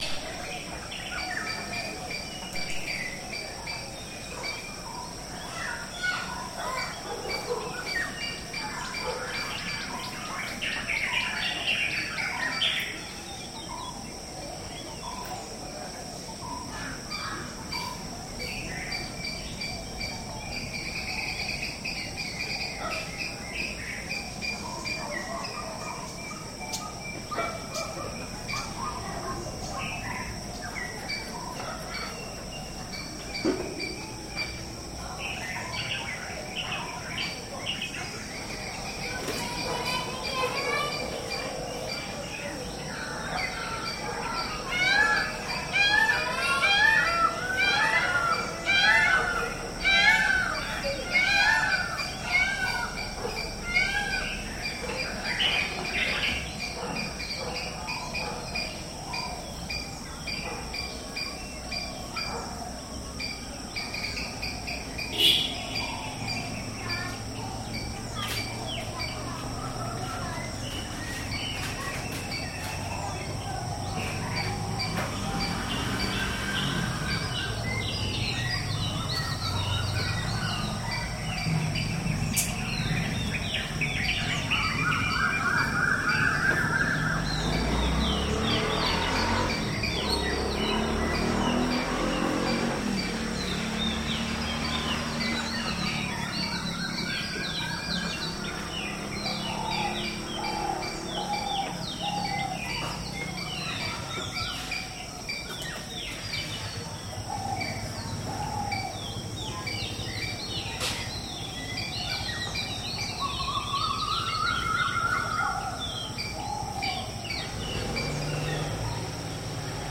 Early morning in Unawatuna
Recorded in Sri Lanka
peacocks, birds and monkeys.